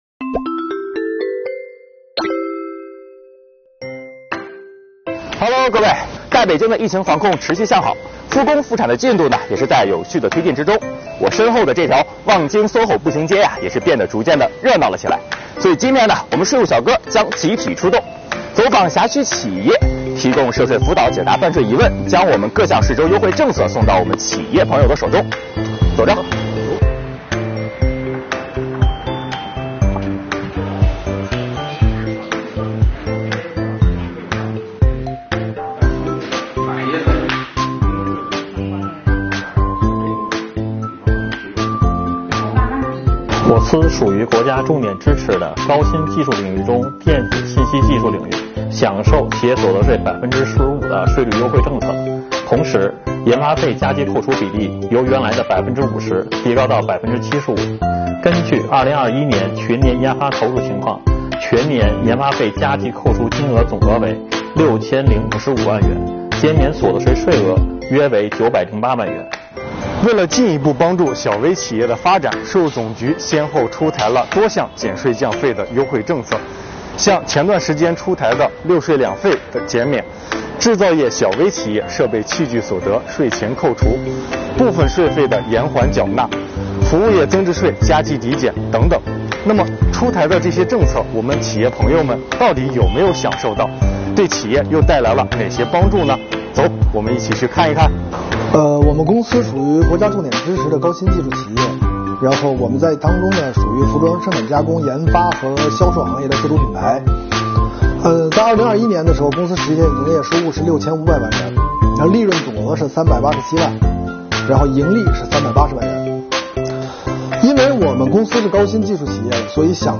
近期，北京的疫情防控持续向好，复工复产有序推进中，望京soho步行街也变的热闹起来了。三位税务小哥走访辖区企业，提供涉税辅导，解答办税疑问，将各项税收优惠政策送到企业手中。